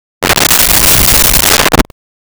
Creature Growl 03
Creature Growl 03.wav